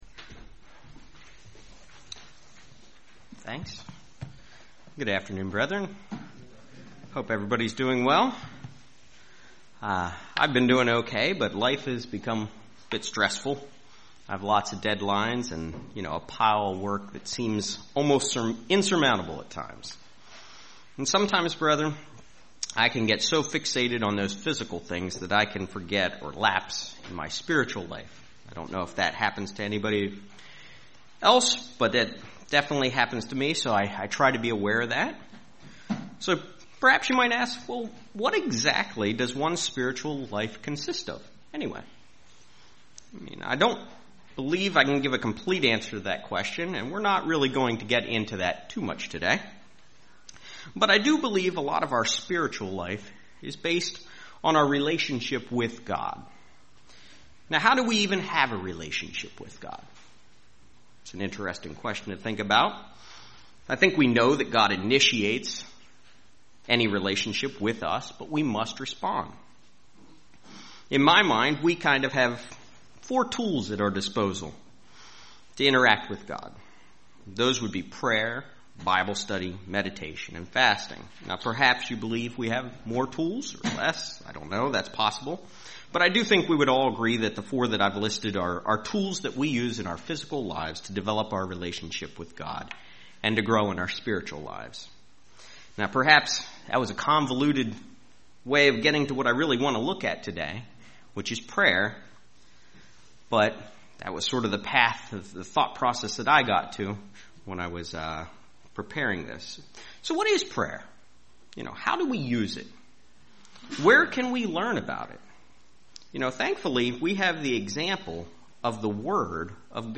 Sermons
Given in Lehigh Valley, PA